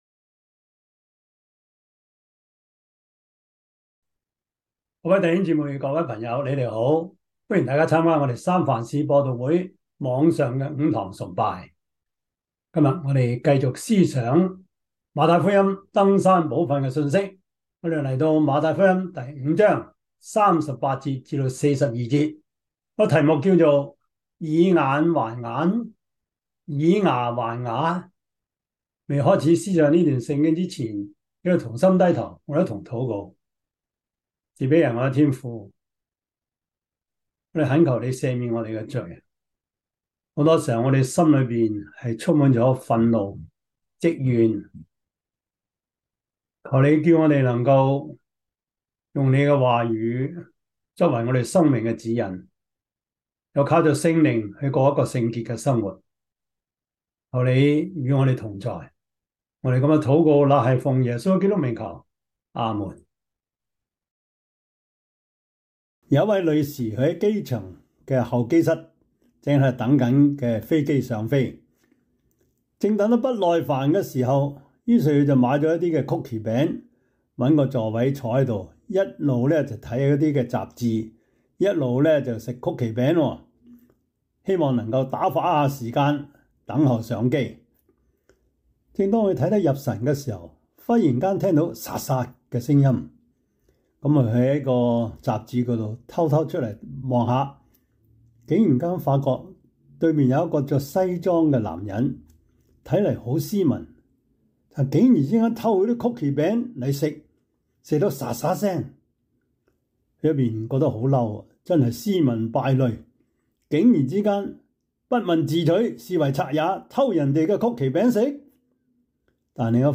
馬太福音 5:38-42 Service Type: 主日崇拜 馬太福音 5:38-42 Chinese Union Version